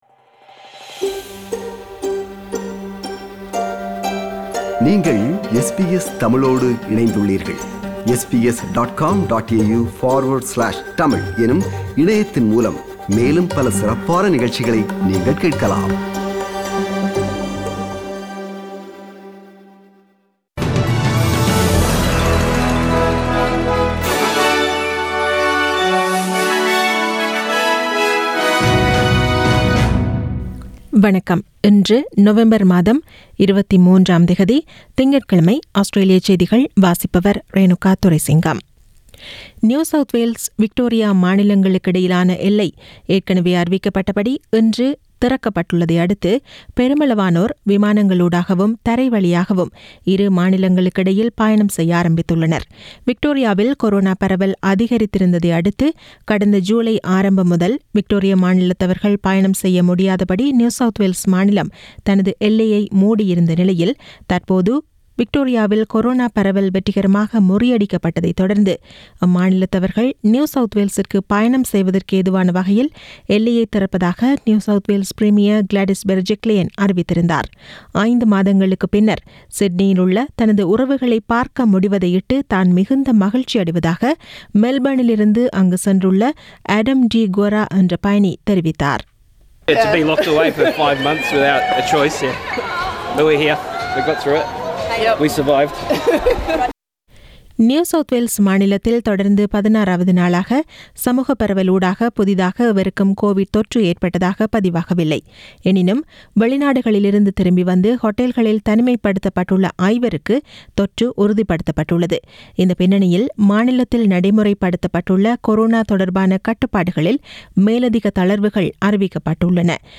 Australian news bulletin for Monday 23 November 2020.